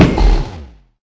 sounds / mob / enderdragon / hit4.ogg
hit4.ogg